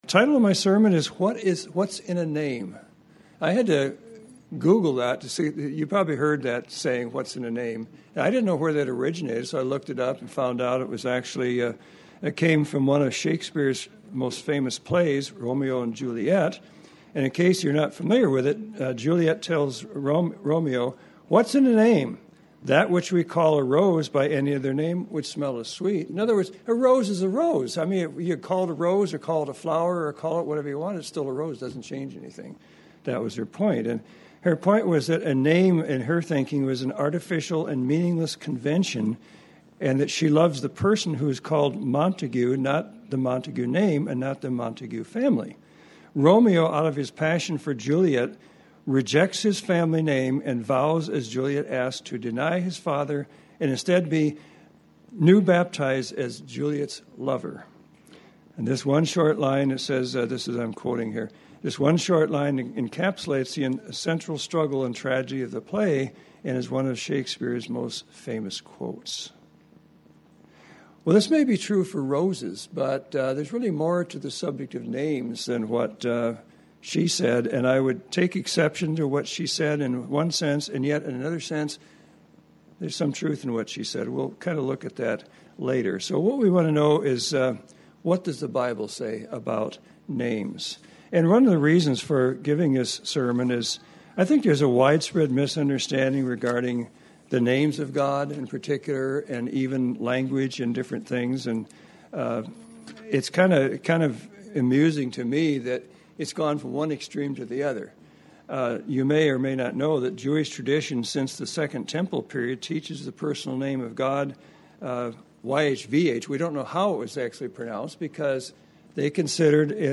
Sermon about the importance of names.